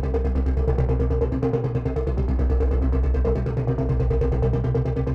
Index of /musicradar/dystopian-drone-samples/Tempo Loops/140bpm
DD_TempoDroneE_140-C.wav